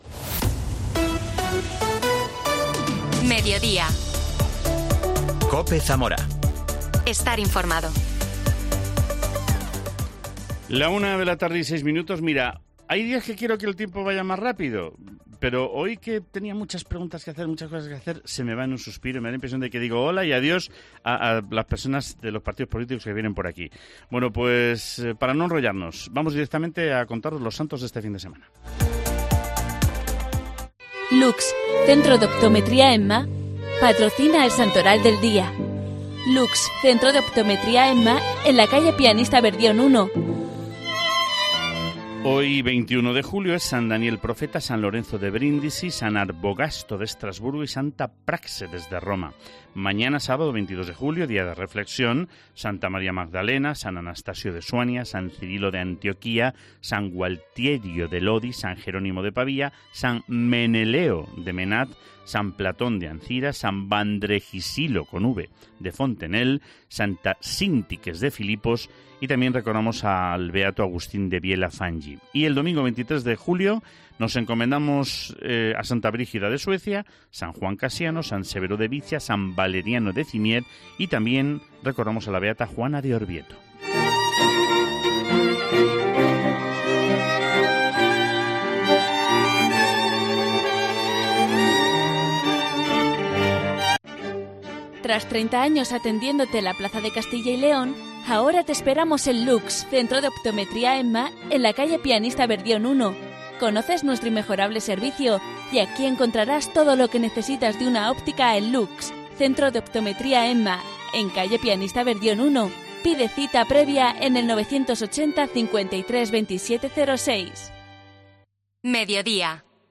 AUDIO: Hablamos con el número 1 del PSOE de Zamora al Congreso, Antidio Fagúndez.